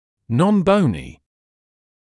[ˌnɔn’bəunɪ][ˌнон’боуни]некостный